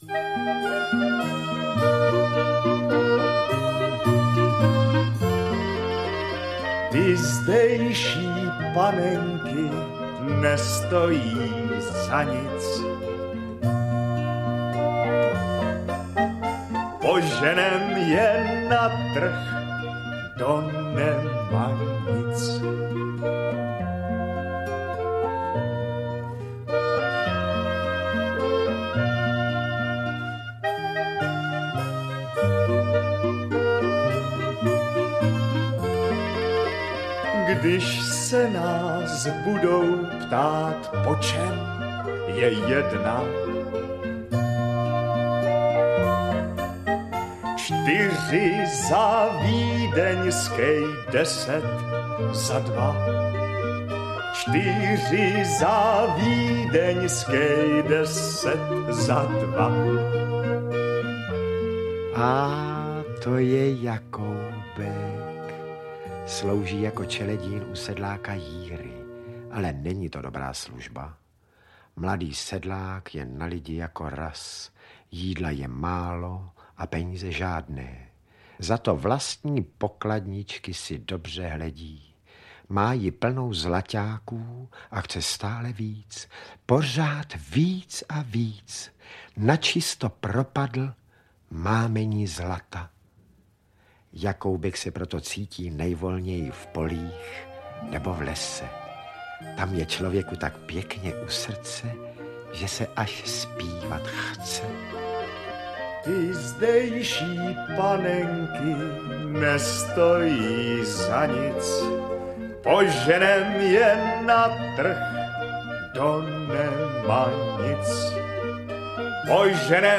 Audiokniha
stáhnout ukázku Varianty: Vyberte Audiokniha 69 Kč Další informace: Čte: Vlastimil Brodský, Vlasta Jelínková,…